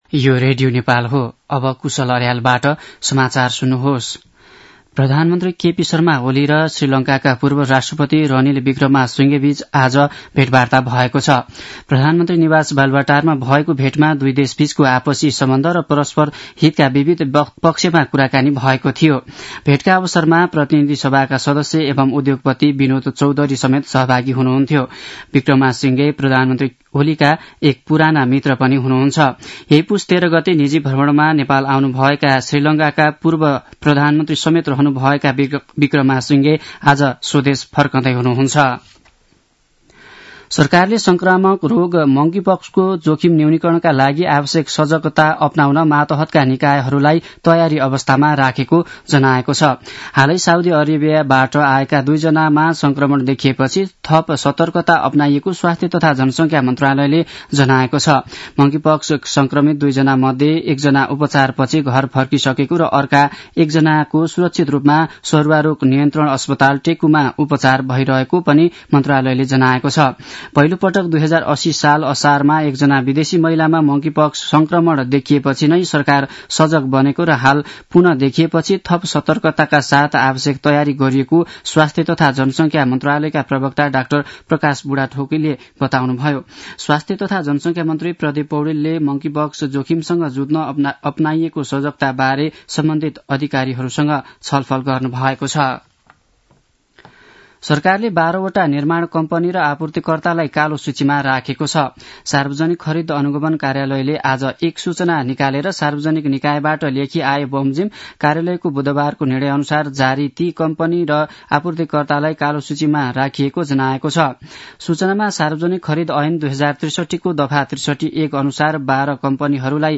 मध्यान्ह १२ बजेको नेपाली समाचार : १९ पुष , २०८१
12-am-nepali-news-1-1.mp3